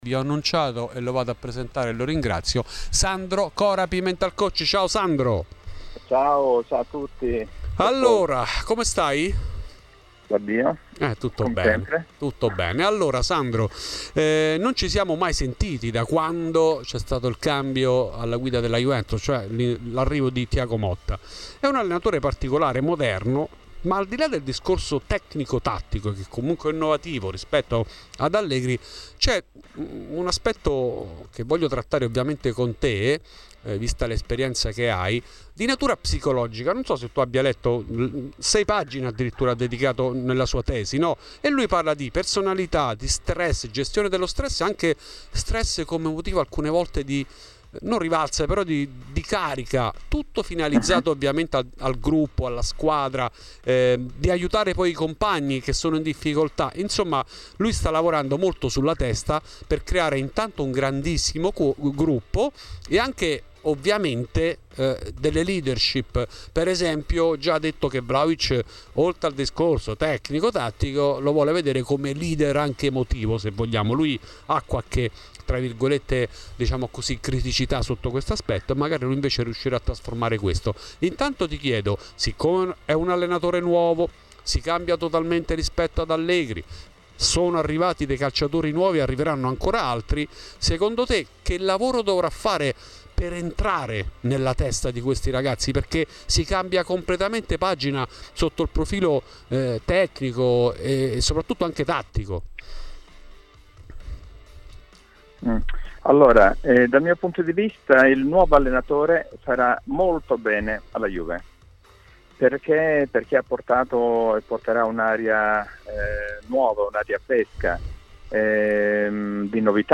in collegamento a " Fuori di Juve ", su Radio Bianconera